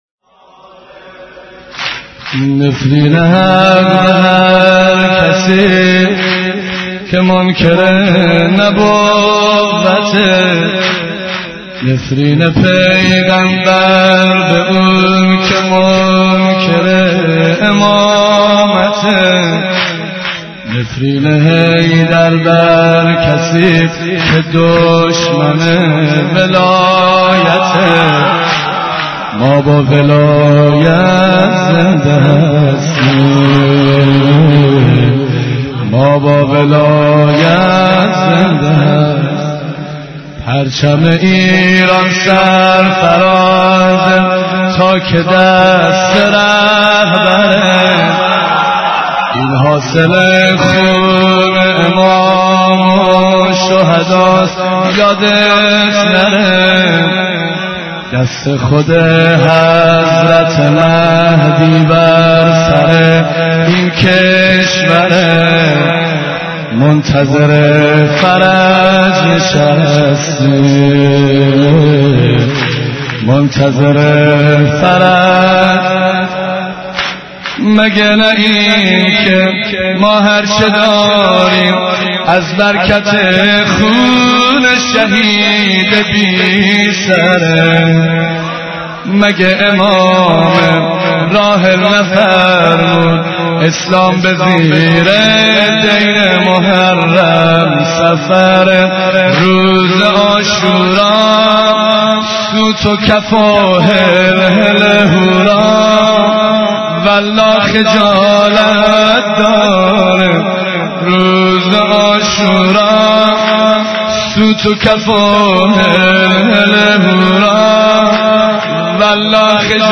مداحی روز عاشورای 88